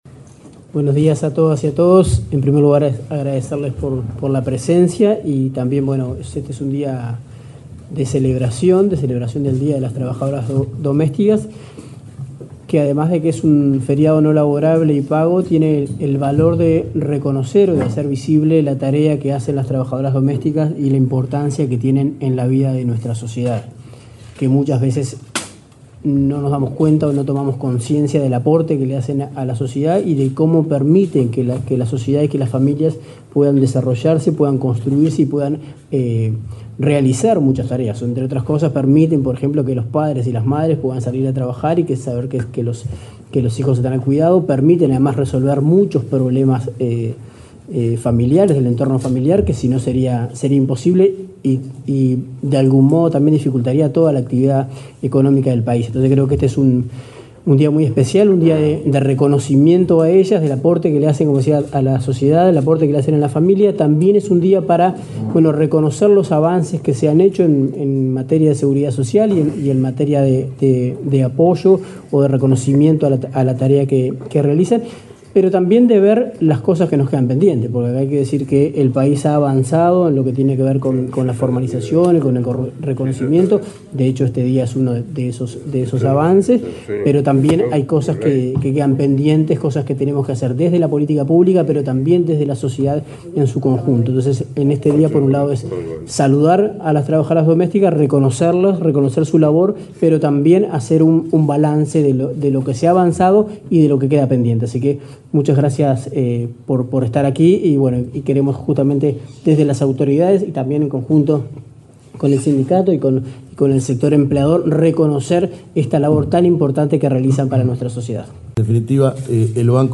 Palabras de autoridades en el Ministerio de Trabajo 19/08/2024 Compartir Facebook X Copiar enlace WhatsApp LinkedIn Este lunes 19, el subsecretario de Trabajo, Daniel Pérez; el presidente del Banco de Previsión Social, Alfredo Cabrera, y el ministro de Trabajo, Mario Arizti, realizaron una conferencia de prensa con motivo del Día de la Trabajadora Doméstica.